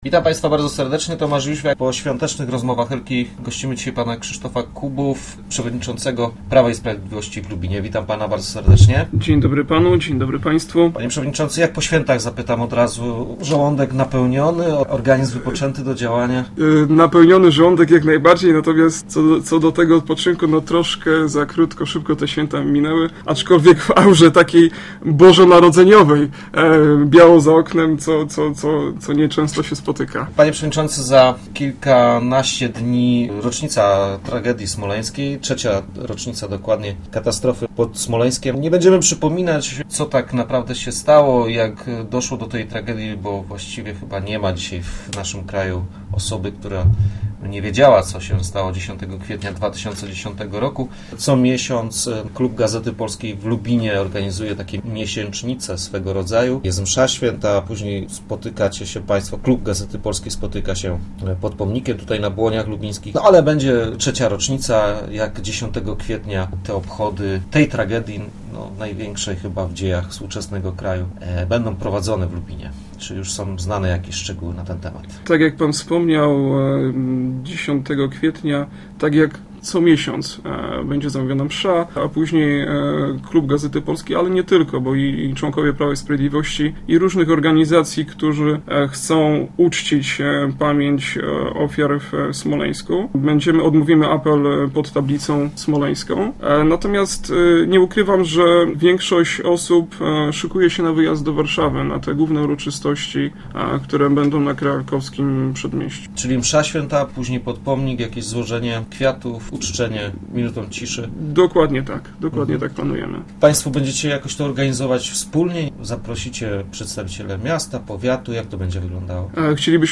W jaki sposób obchody tej tragedii będą przebiegały w Lubinie? Naszym gościem był Krzysztof Kubów, szef lubińskich struktur Prawa i Sprawiedliwości.